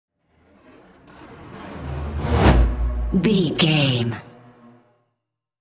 Whoosh electronic fast
Sound Effects
Atonal
Fast
futuristic
high tech
intense
sci fi